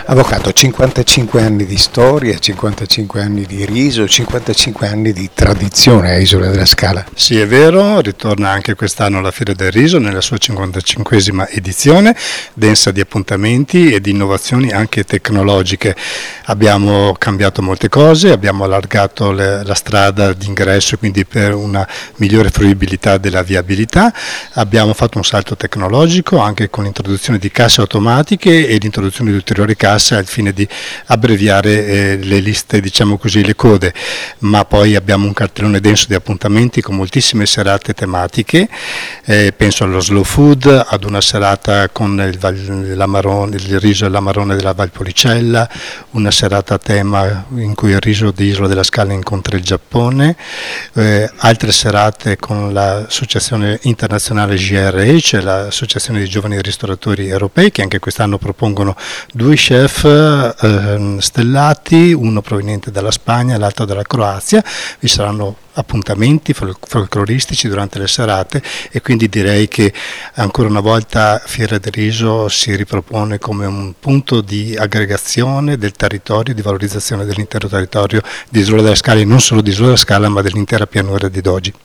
Le dichiarazioni raccolte dal nostro corrispondente